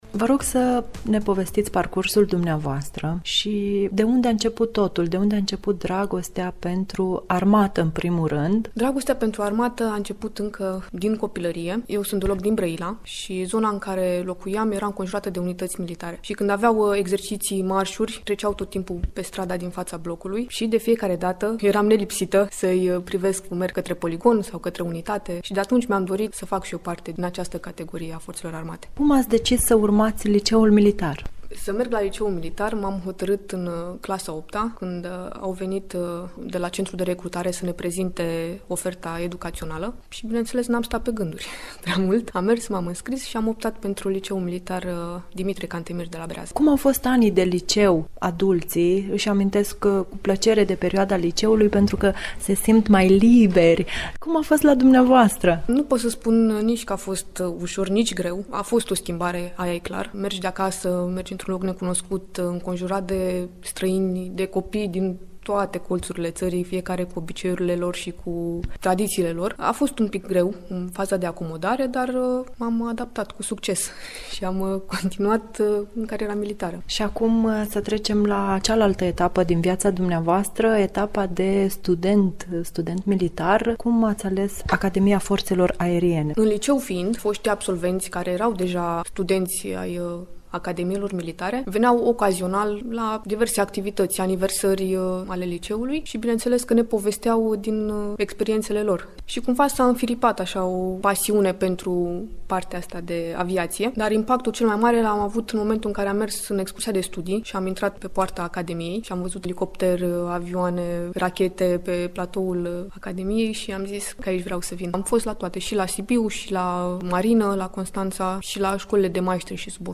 Reportaj.mp3